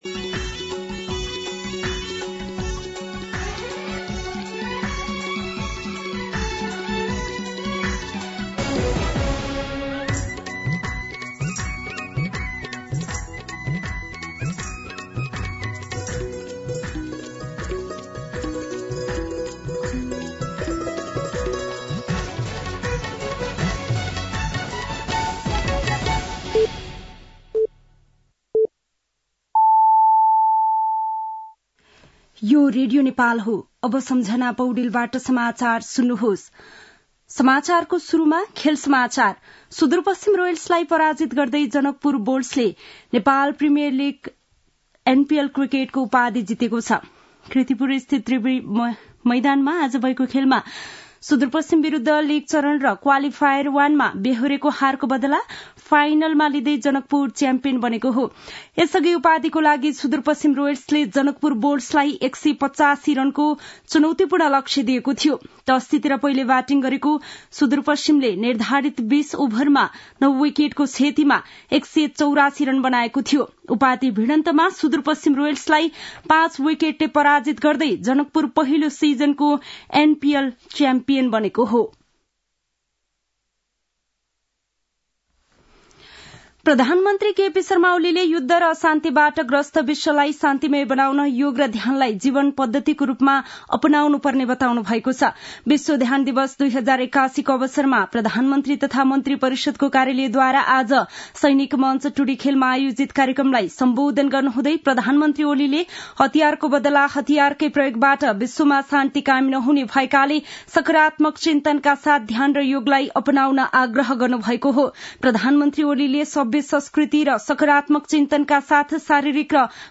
दिउँसो ४ बजेको नेपाली समाचार : ७ पुष , २०८१
4pm-Nepali-News.mp3